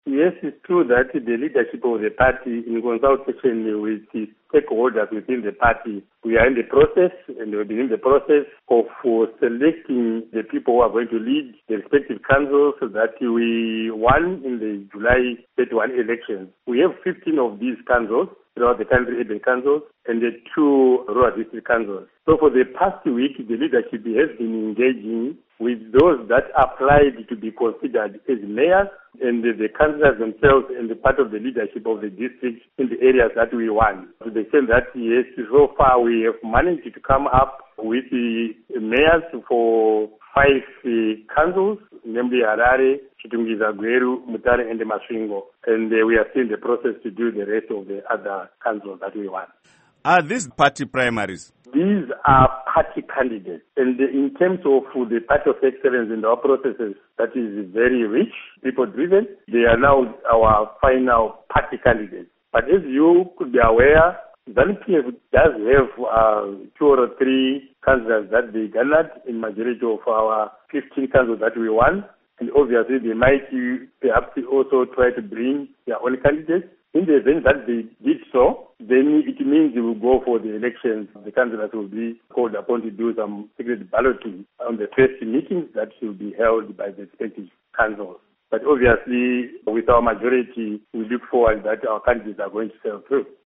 Interview With Blessing Chebundo